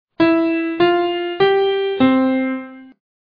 Major key and mode